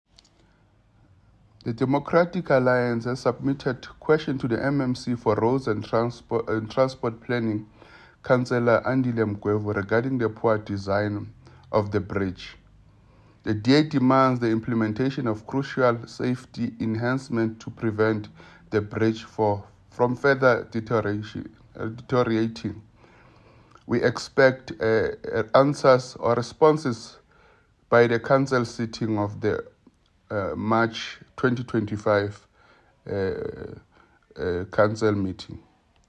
Note to Editors: Please find an English soundbite by Cllr Fana Nkosi